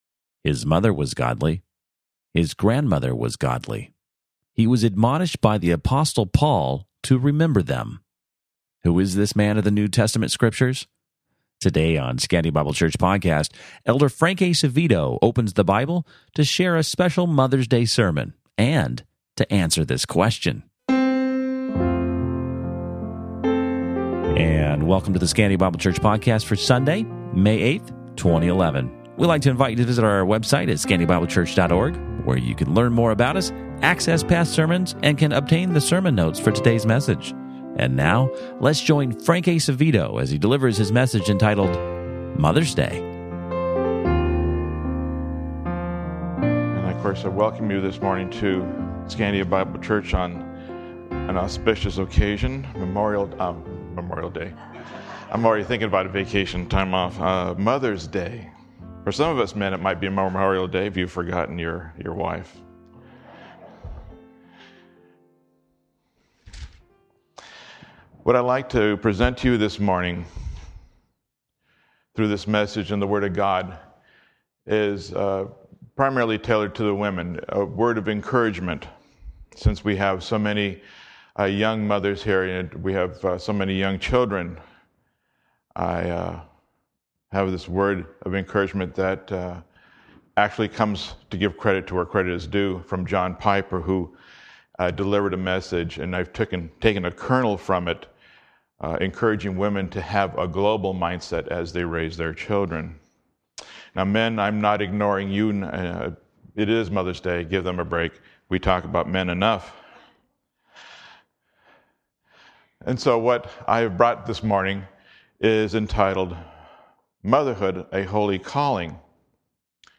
Sermon Notes Date